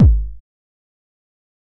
nightcore-kick.wav